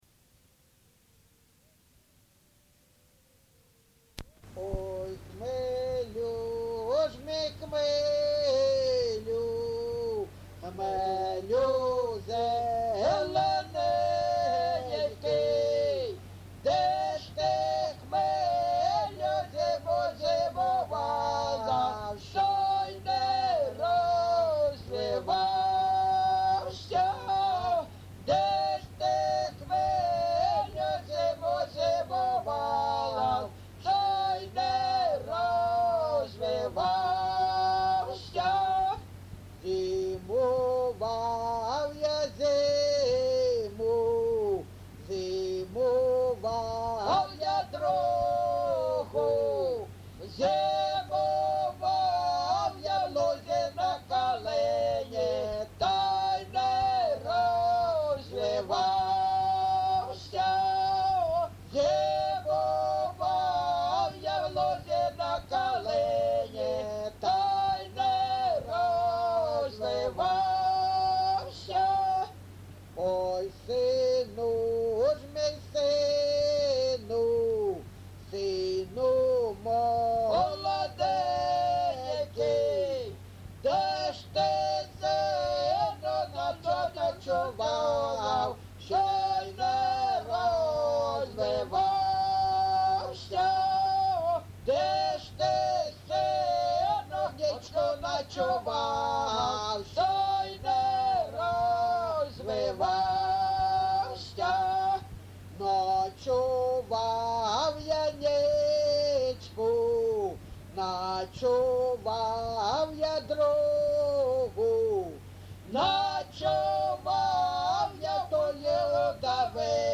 ЖанрПісні з особистого та родинного життя
Місце записус. Григорівка, Артемівський (Бахмутський) район, Донецька обл., Україна, Слобожанщина